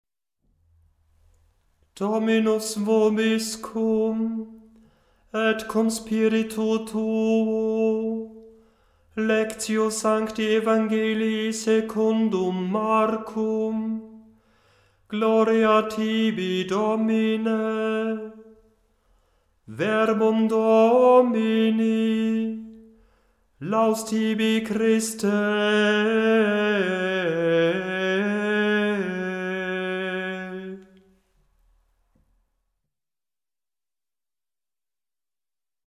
Die verschiedenen Einleitungen sind in der Tonhöhe absteigend geordnet,
Zur Übersicht Liturgische Gesänge
evg_marcum_lat_ton-a1.mp3